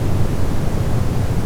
THRUSTER_Weak_loop_mono.wav